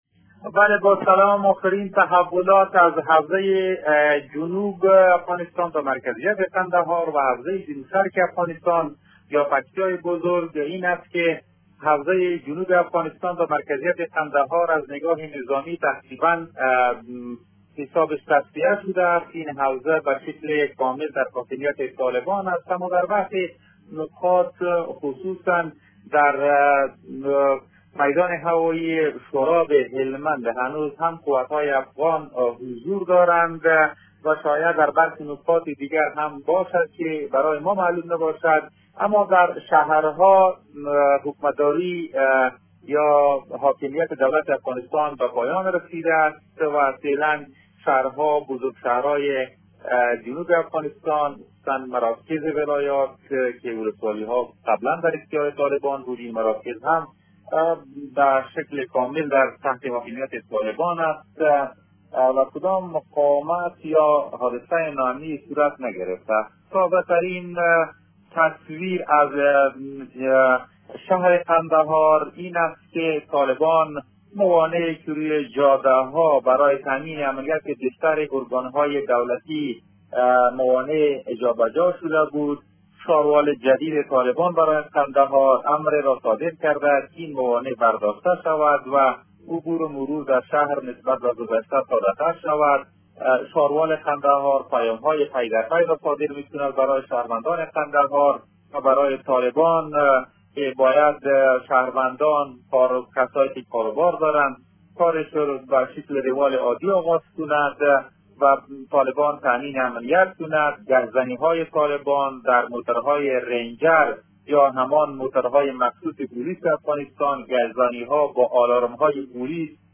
آخرین تحولات از ولایت قندهار و ولایت پکتیا در گزارش تکمیلی خبرنگار رادیو دری از منطقه